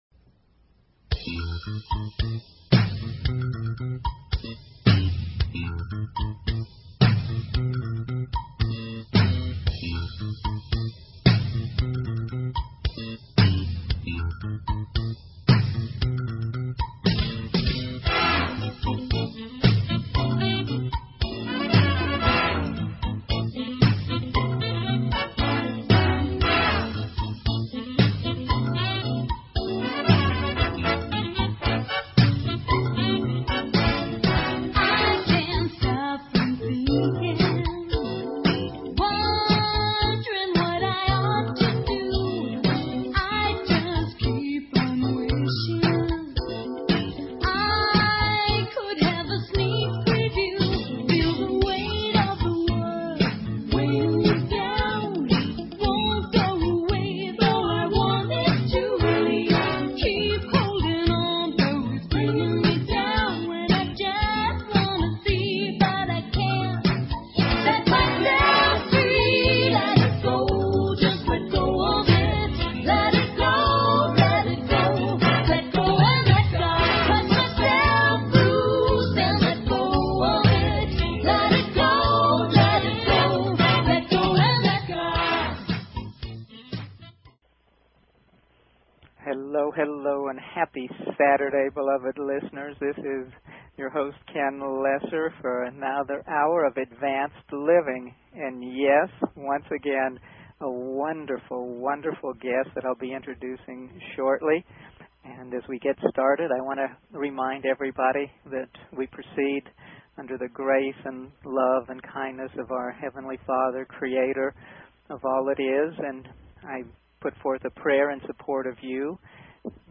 Subscribe Talk Show